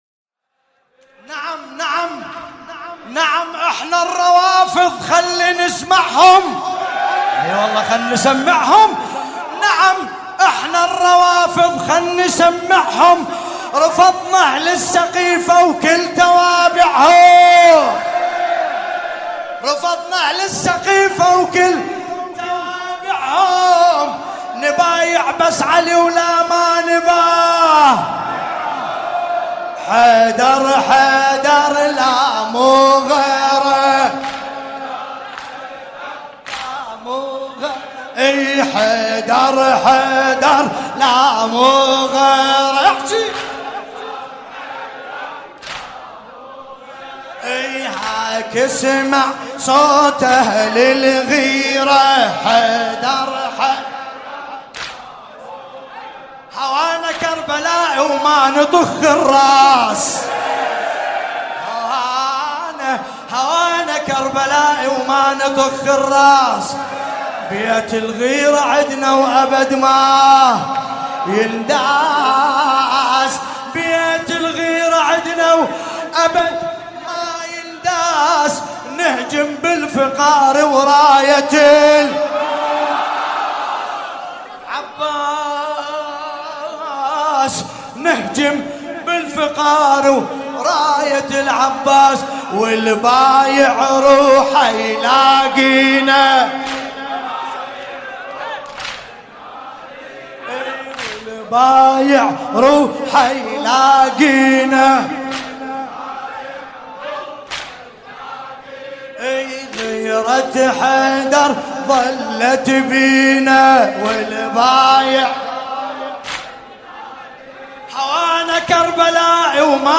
لطمیات ومراثی